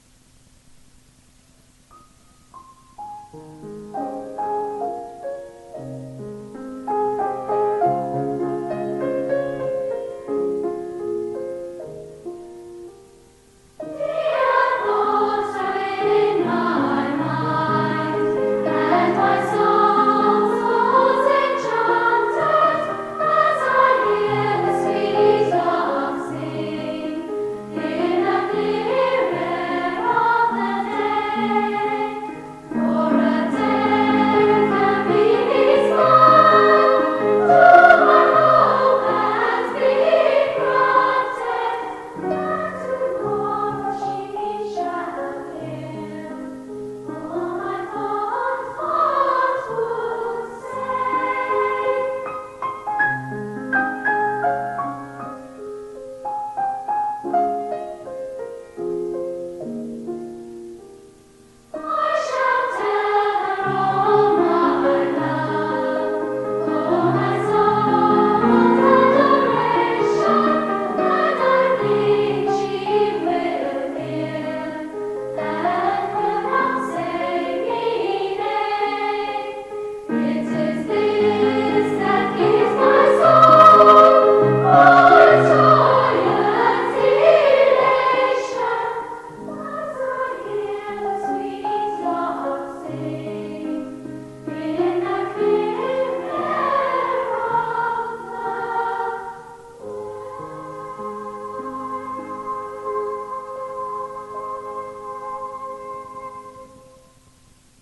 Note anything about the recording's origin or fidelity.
Side A, digitized from cassette tape: